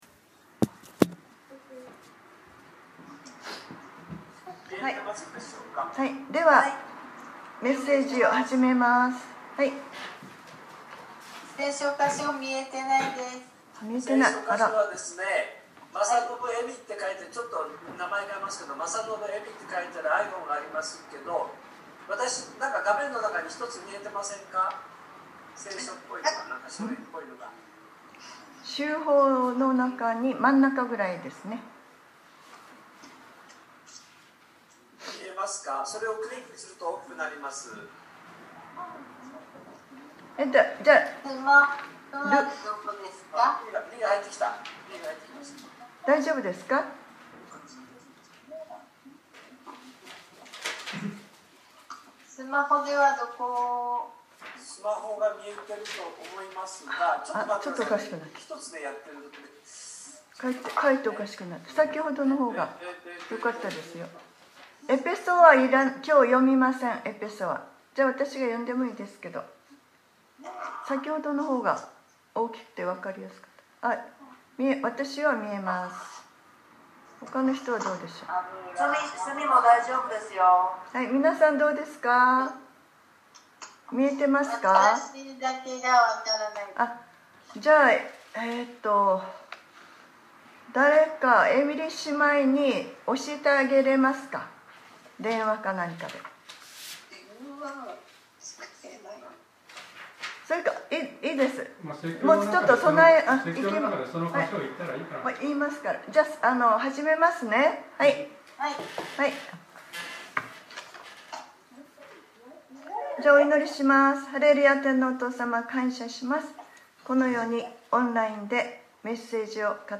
2020年4月19日（日）礼拝説教『身を慎み目を覚ましていなさい』